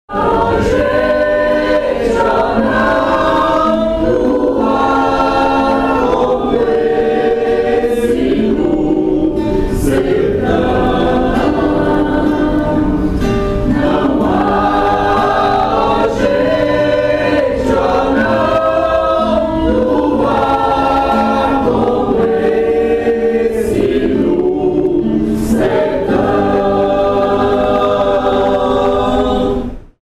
No momento da entrevista, nossa reportagem também acompanhou o ensaio do Coral Dom Maior, onde os cantores gravaram uma palinha de seu repertório popular para nossos ouvintes.